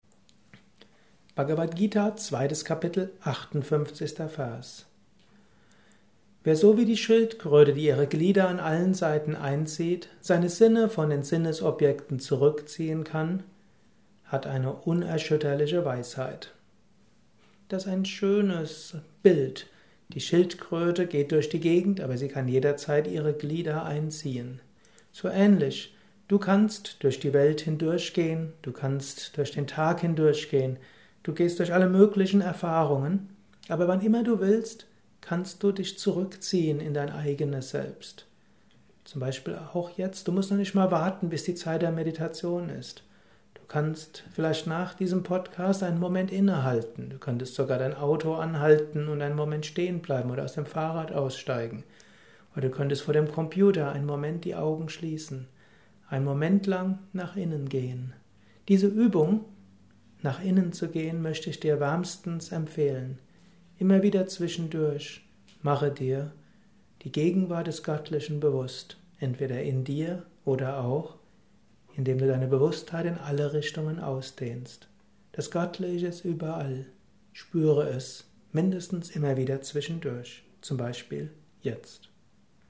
Aufnahme speziell für diesen Podcast.